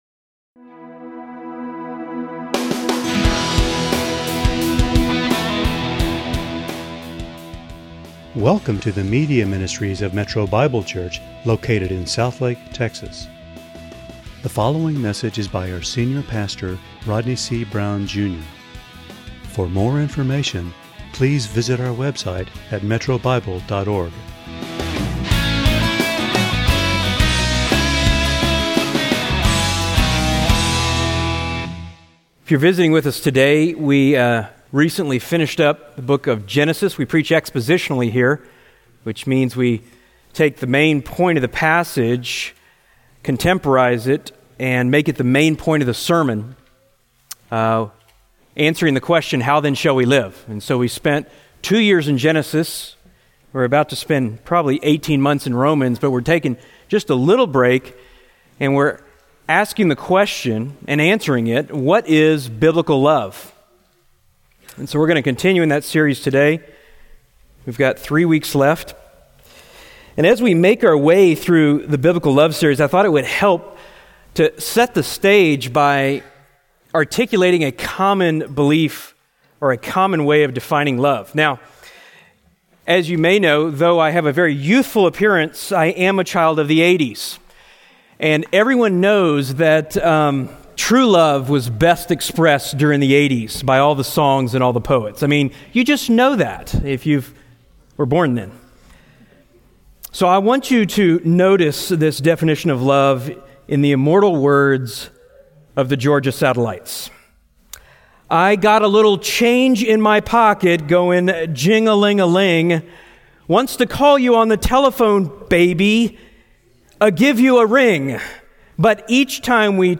× Home About sermons Give Menu All Messages All Sermons By Book By Type By Series By Year By Book How Do We Love as a Church?